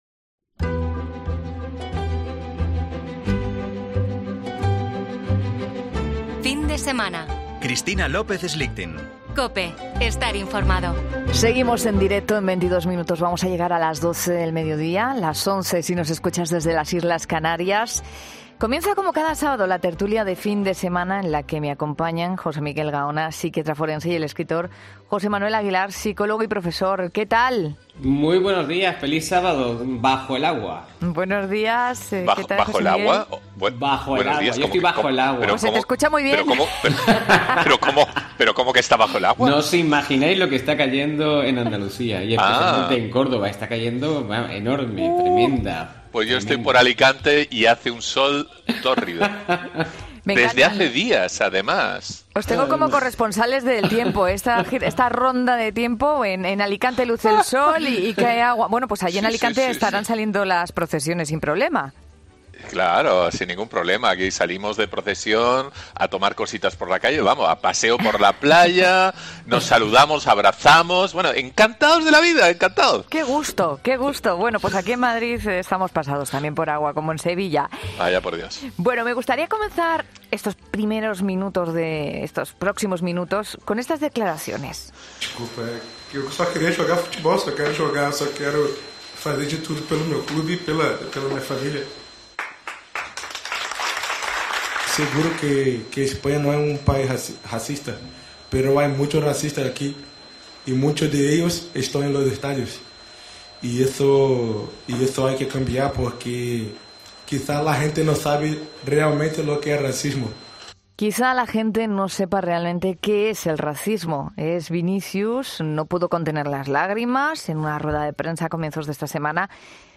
ESCUCHA LA TERTULIA DE FIN DE SEMANA